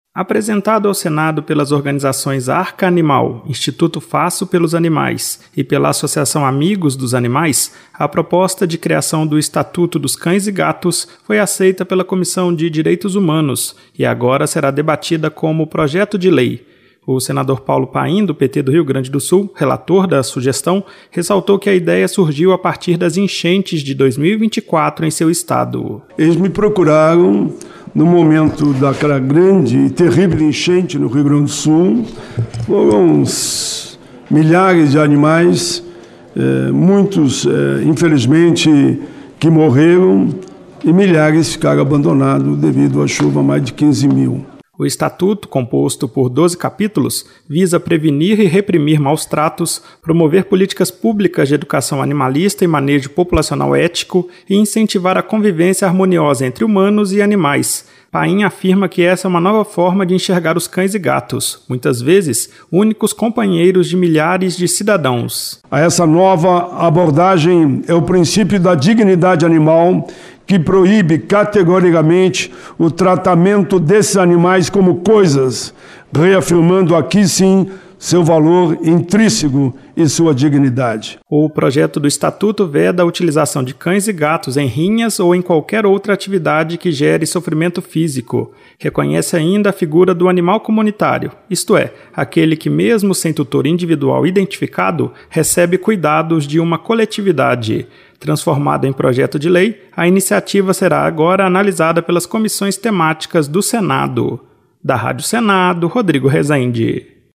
O senador Paulo Paim (PT-RS) destacou que a ideia nasceu após as enchentes de 2024 no Rio Grande do Sul, que deixaram milhares de animais descuidados. O estatuto prevê proteção integral, combate a maus-tratos e reconhecimento do animal comunitário.